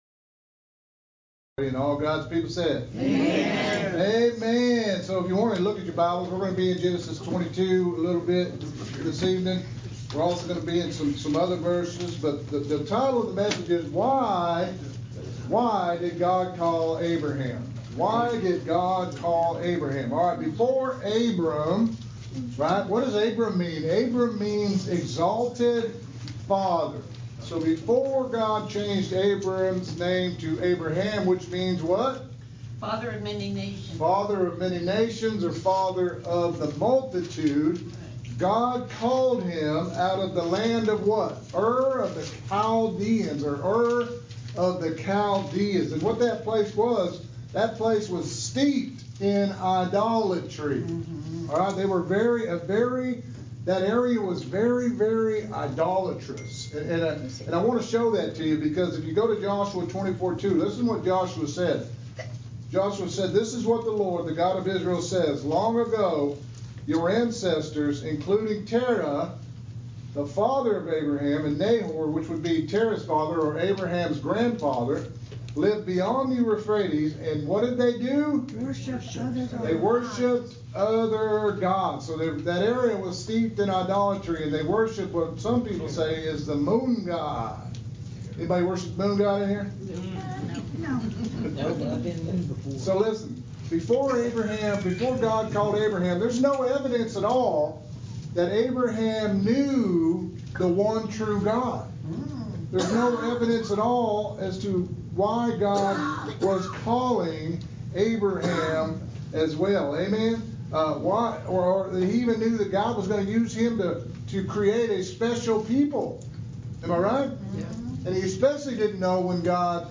Interactive Bible Study
Sermon